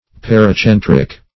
paracentric \par`a*cen"tric\ (p[a^]r`[.a]*s[e^]n"tr[i^]k),